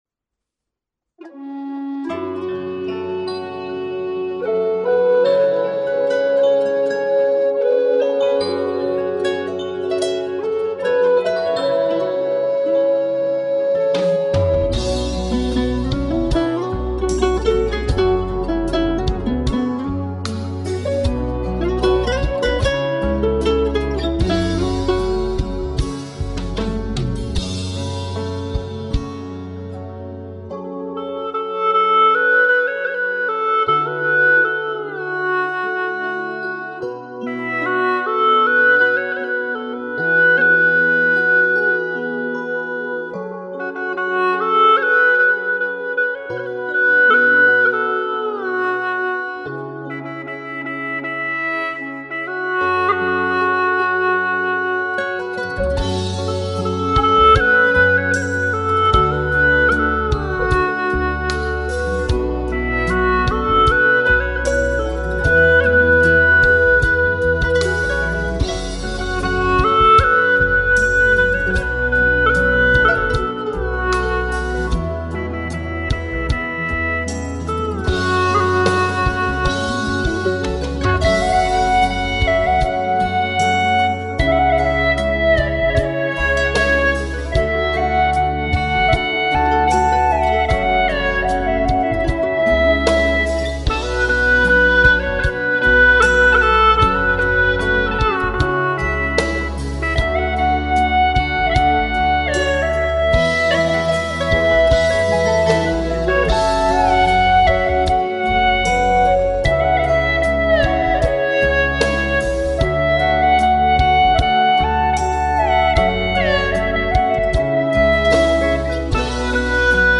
调式 : A 曲类 : 流行